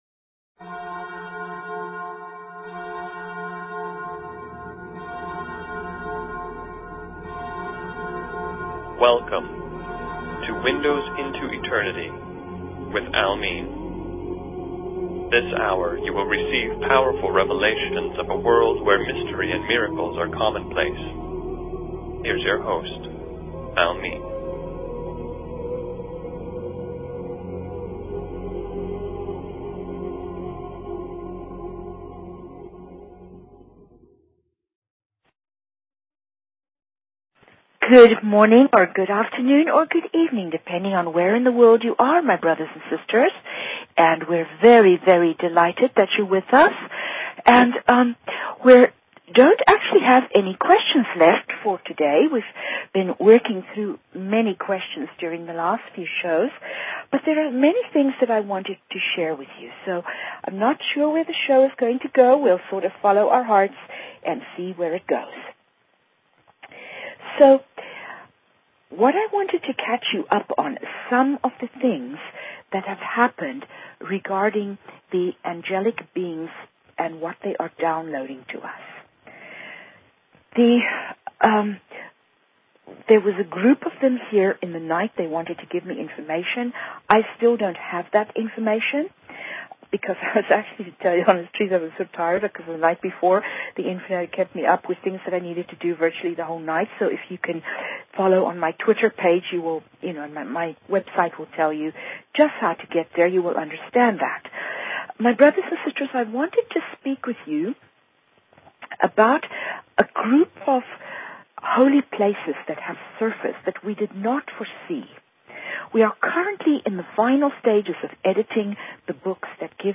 Talk Show Episode, Audio Podcast, Windows_Into_Eternity and Courtesy of BBS Radio on , show guests , about , categorized as